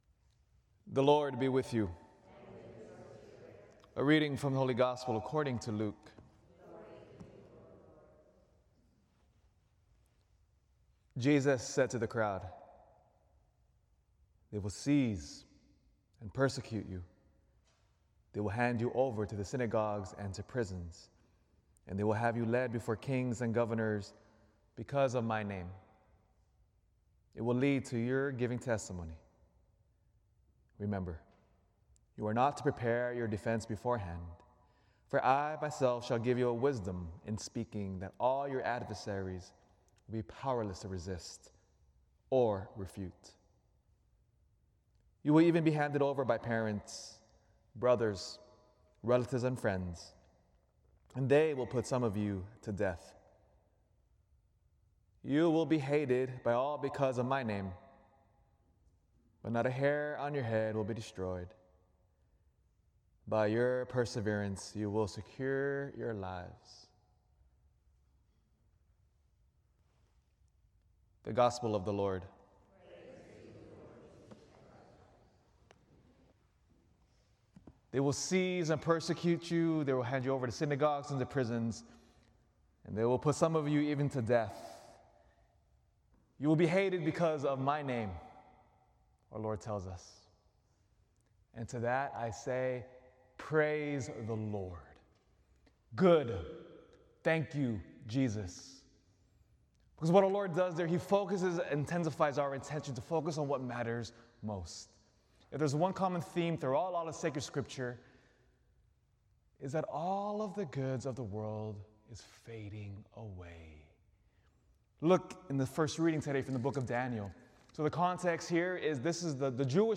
Prison